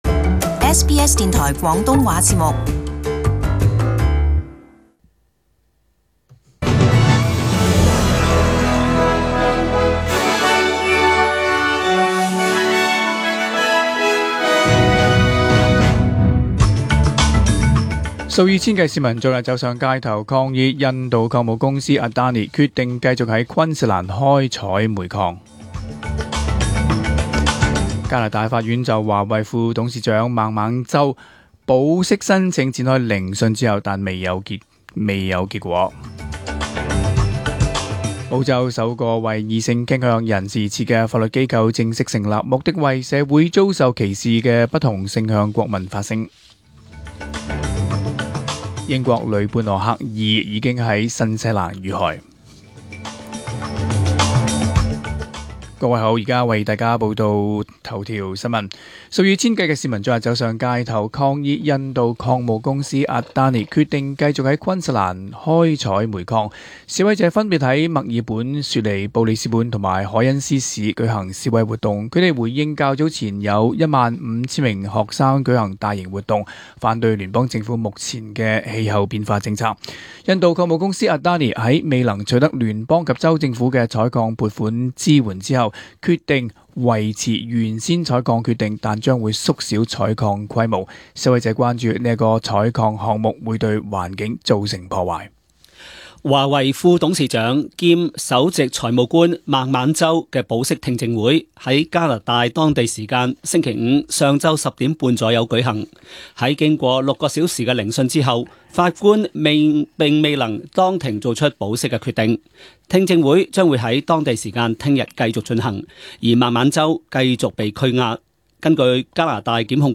2018年12月9日广东话星期日10点钟新闻